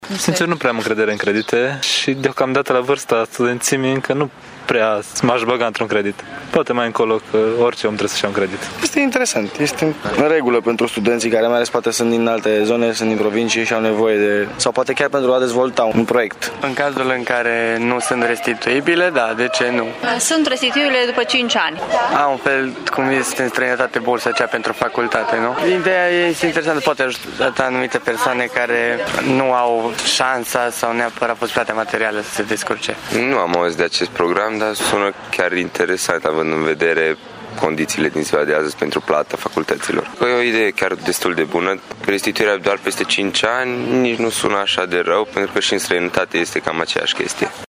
Studenții mureșeni nu cred, însă, la fel. Ei consideră măsura europeană: